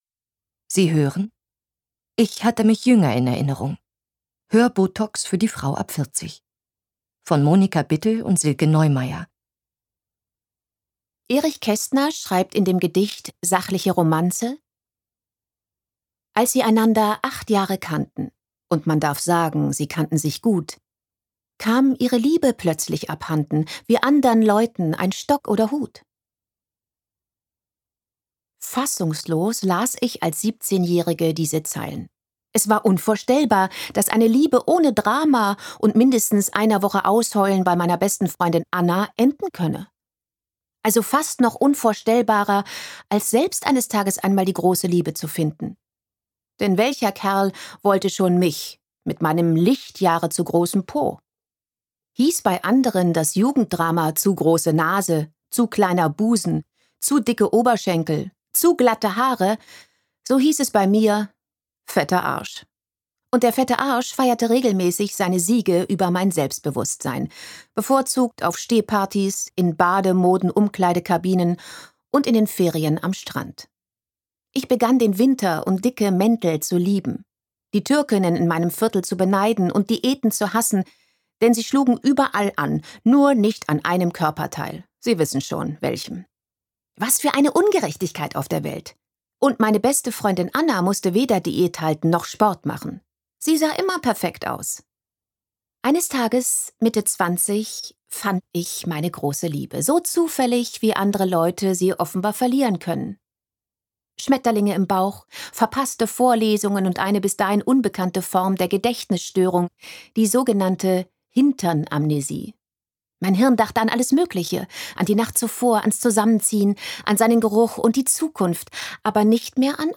2016 | 3. Auflage, Gekürzte Ausgabe